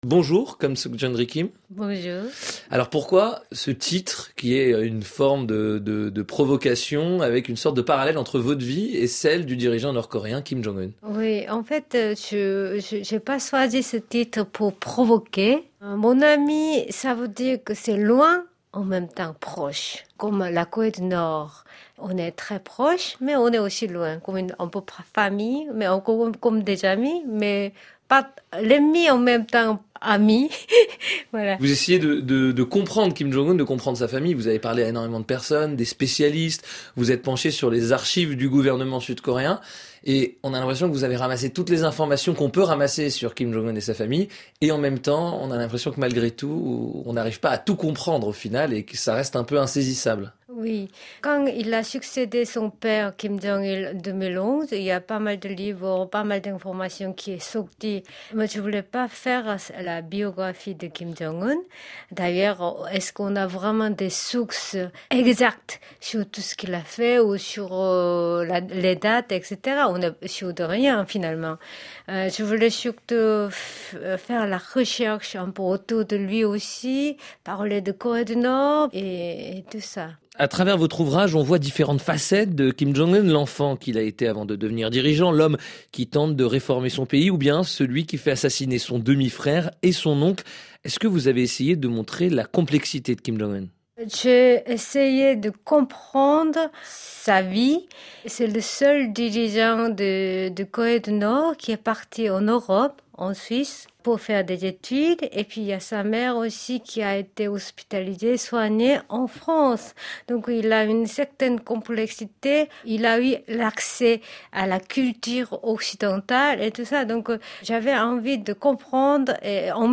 Tous les samedis, un journaliste de la rédaction rencontre un auteur de livre consacré à l’actualité internationale. L’occasion d’approfondir un sujet précis qui fait -ou non- la Une de l’actualité internationale.